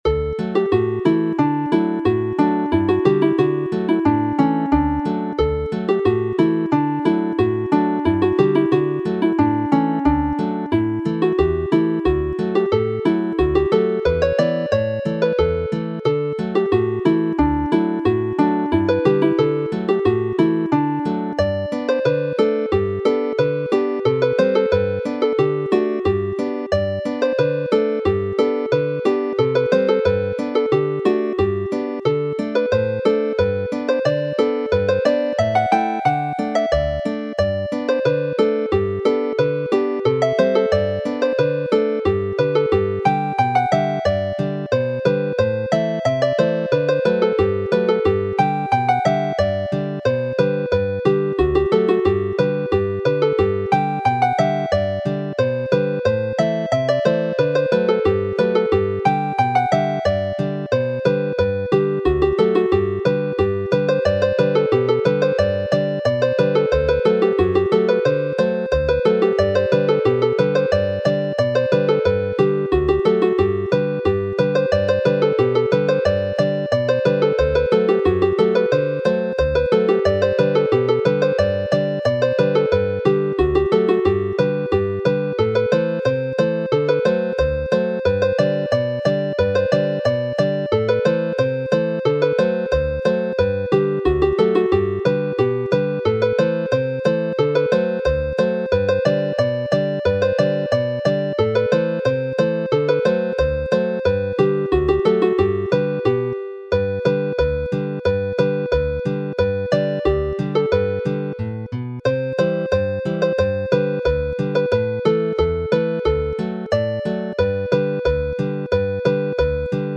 a song and a dance (polka)